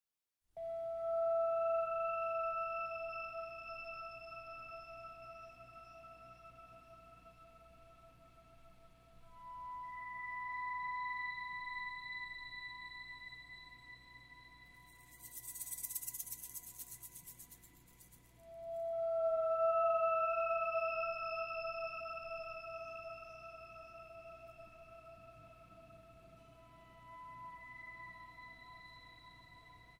Guitar and Processed Guitar
Acoustic Bass
Drums and Percussion